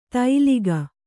♪ tailiga